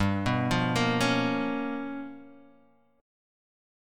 G7#9b5 chord